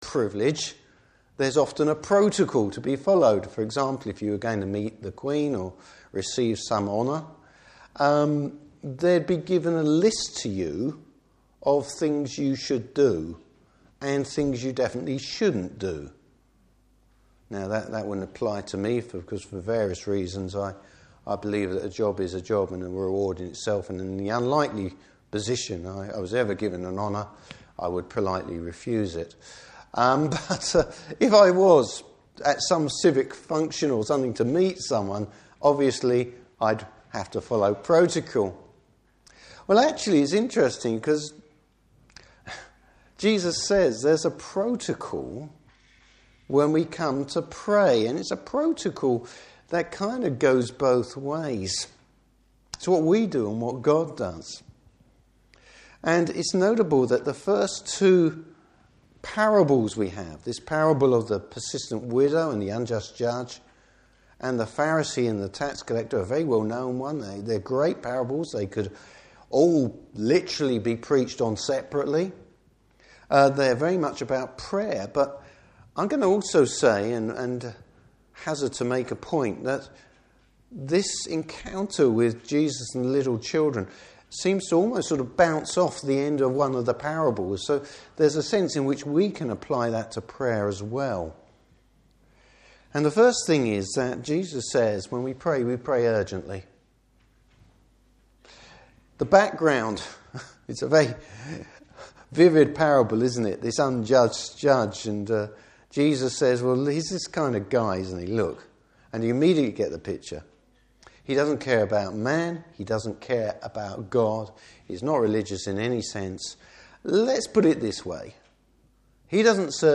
Service Type: Morning Service Bible Text: Luke 18:1-17.